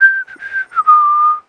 welder-whistle2.wav